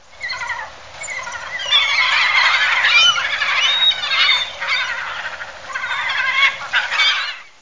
serebristaya-chajka-larus-argentatus_49.mp3